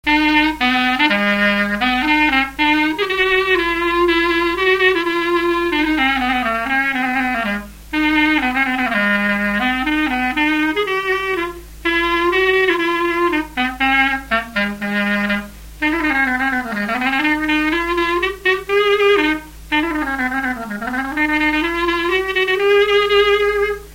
Résumé instrumental
circonstance : fiançaille, noce
Catégorie Pièce musicale inédite